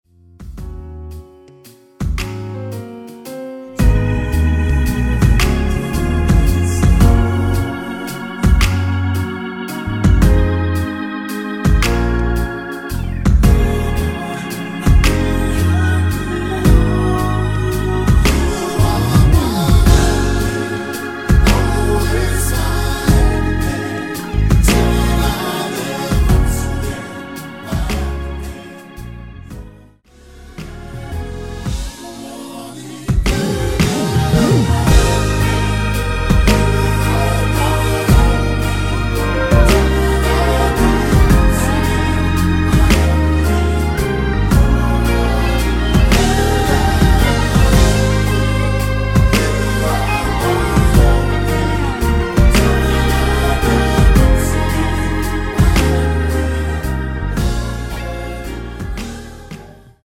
(-2) 내린코러스 포함된 MR 입니다.(미리듣기 참조)
Db
앞부분30초, 뒷부분30초씩 편집해서 올려 드리고 있습니다.